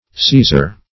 caesar \c[ae]"sar\ (s[=e]"z[~e]r), n. [L.]